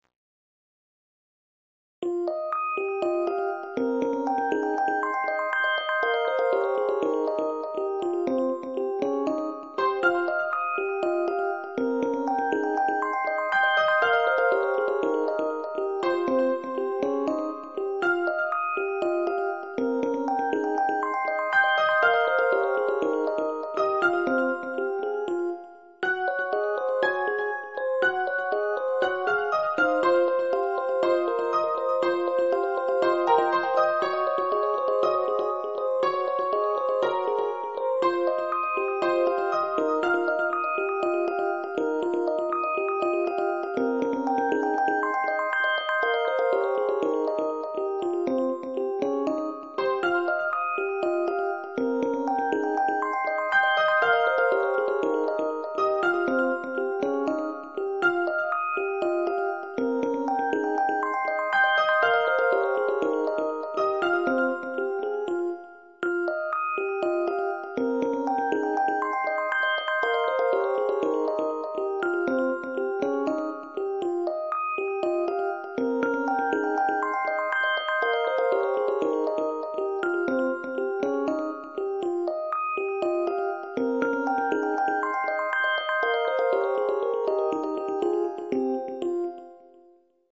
作業用BGM] 水中都市